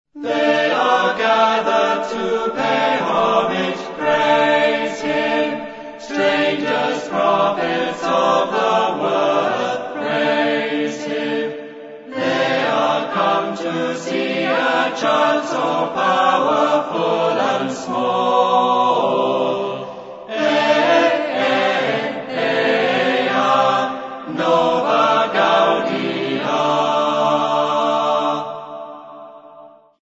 First part, 0:29 sec, mono, 22 Khz, file size: 118 Kb.